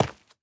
Minecraft Version Minecraft Version 1.21.5 Latest Release | Latest Snapshot 1.21.5 / assets / minecraft / sounds / block / dripstone / break5.ogg Compare With Compare With Latest Release | Latest Snapshot
break5.ogg